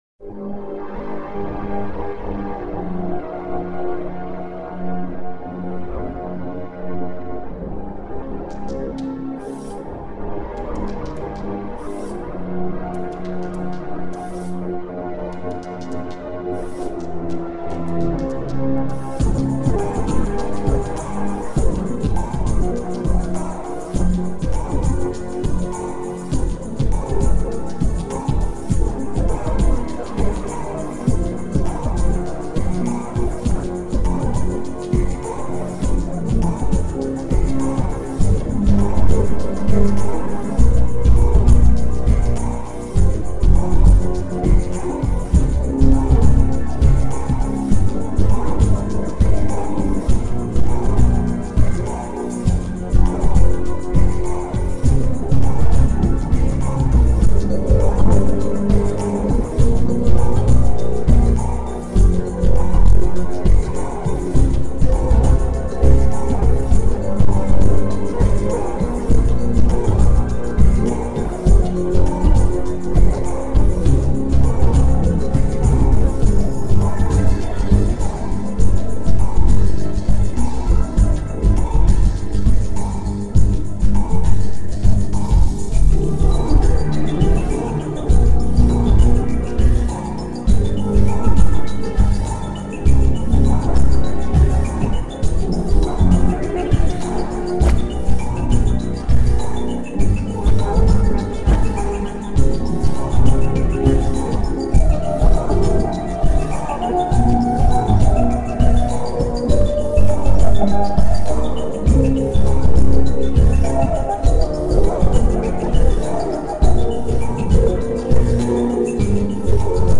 in 8D mode